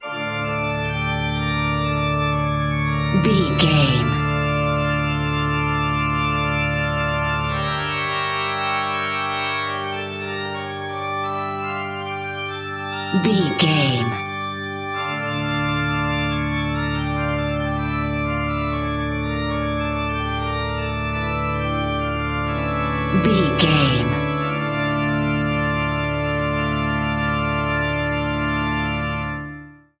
Aeolian/Minor
D
eerie
ominous
mournful